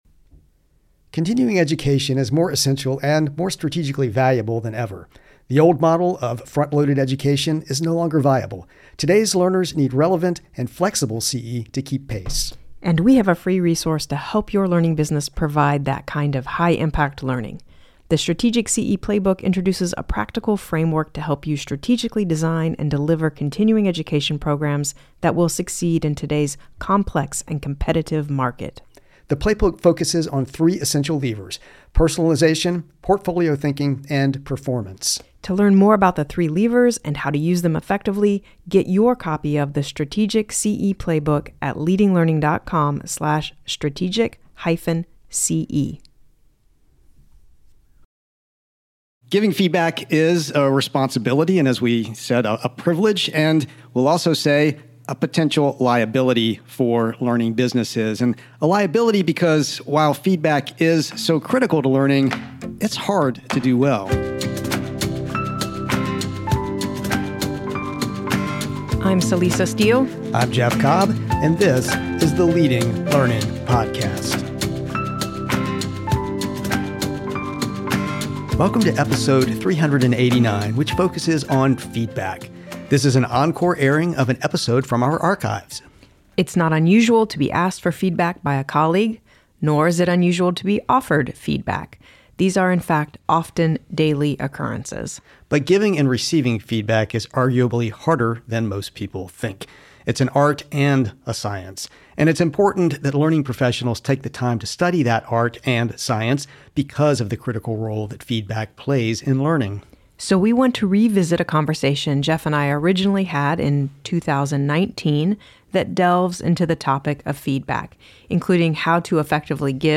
co-hosts